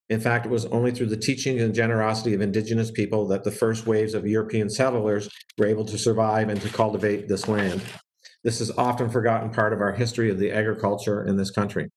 Parliamentary Secretary to the Minister of Agriculture and Agri-Food and Bay of Quinte riding MP Neil Ellis as he speaks at a virtual announcement Friday (October 9/20)